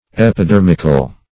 Epidermical \Ep`i*der"mic*al\, a.